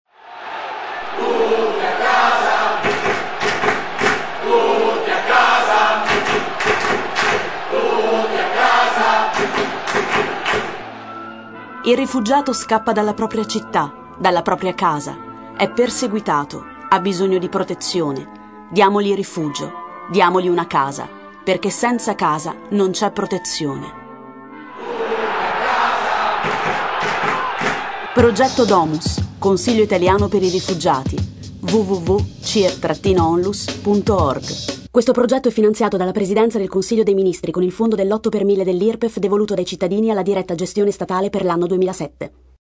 Per la Campagna sono stati realizzati il logo e l’immagine coordinata; materiali editoriali (brochure, cartellina convegno, flayer bus, segnalibri e locandina) e lo Spot radio.